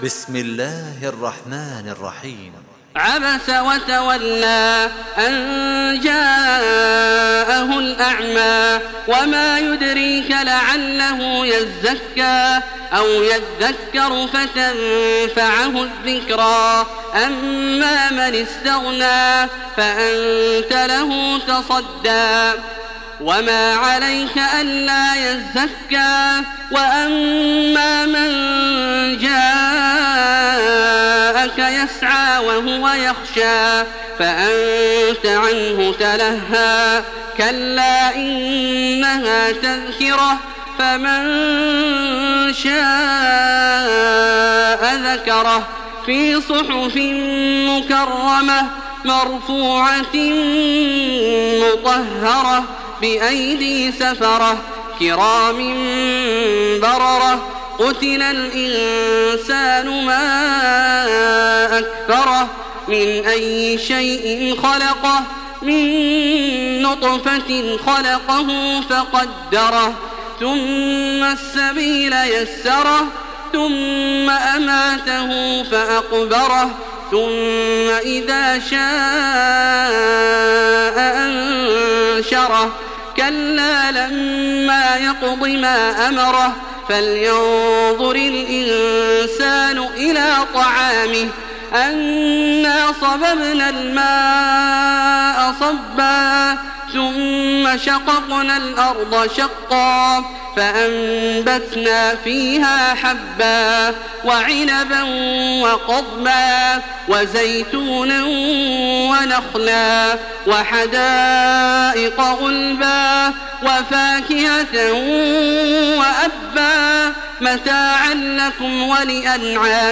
Surah ‘আবাসা MP3 by Makkah Taraweeh 1427 in Hafs An Asim narration.
Murattal